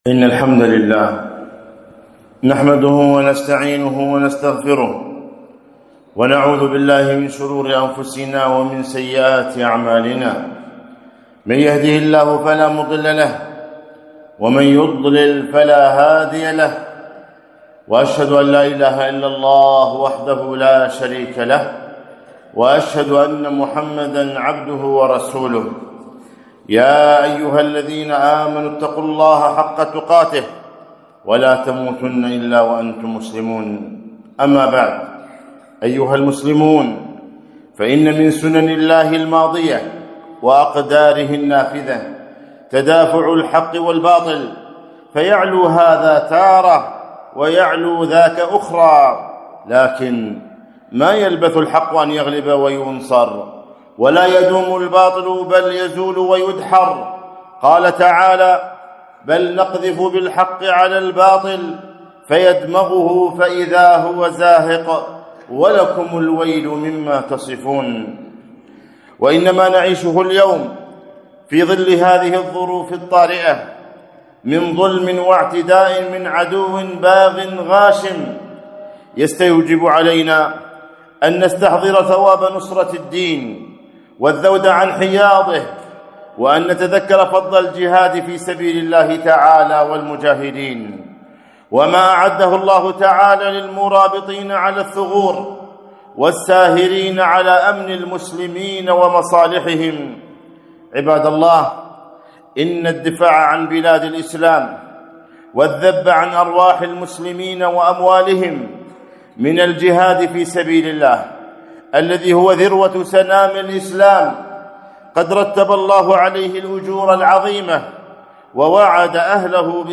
خطبة - فضل الرباط والمرابطين